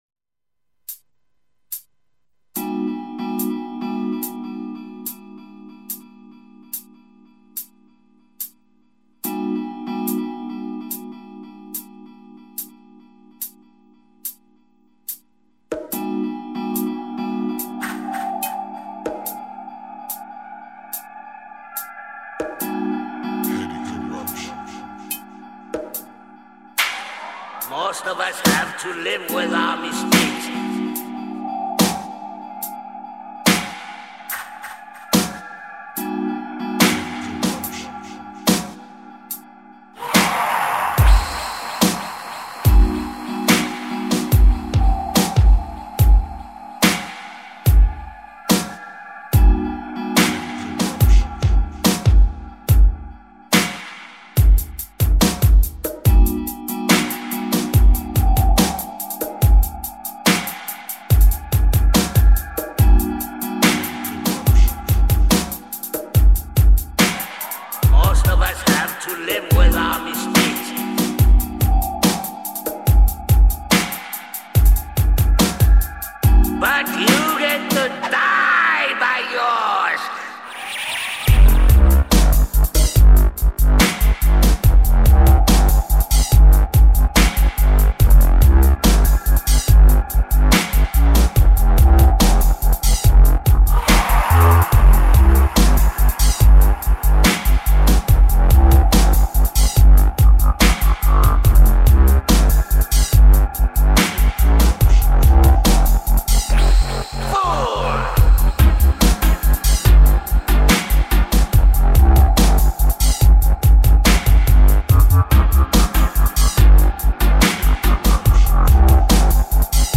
Only a couple pull ups and not much chat....mostly music!